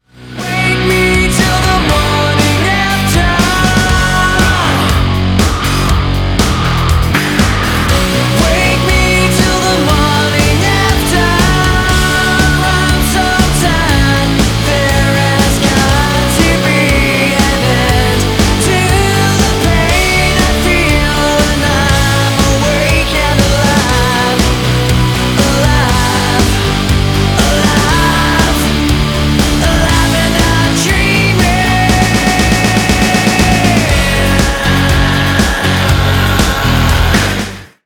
• Качество: 307, Stereo
Electronic
Alternative Rock